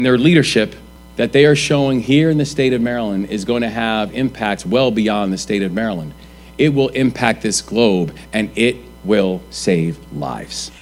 Governor Wes Moore said the investment underscores the state’s leadership in life sciences and biomanufacturing…